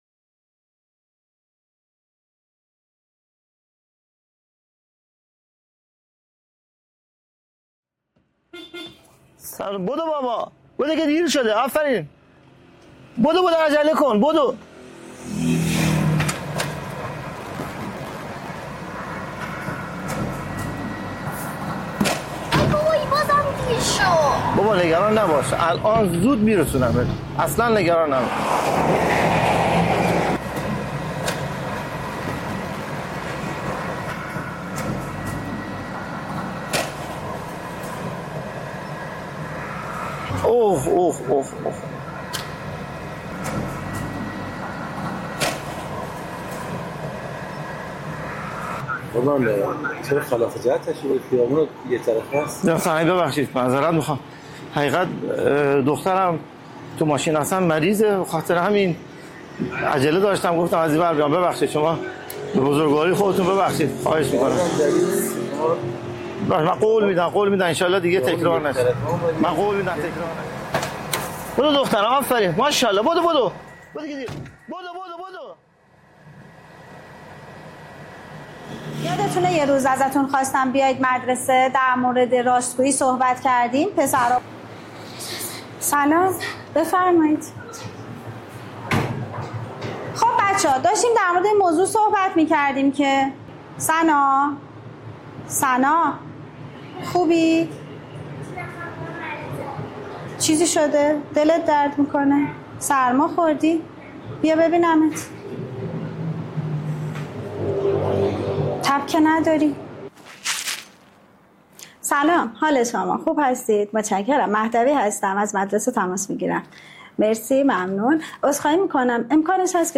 پرانرژی و کودکانه‌ی
ژانر: سرود